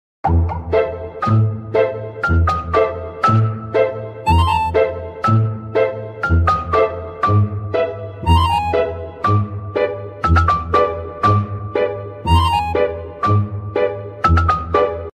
Kucing Meong Miaw Miaw Sound Effects Free Download